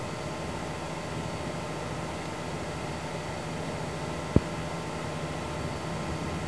ファンノイズ比較